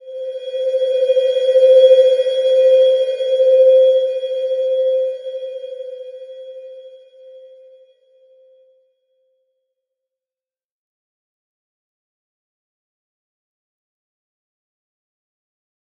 Slow-Distant-Chime-C5-f.wav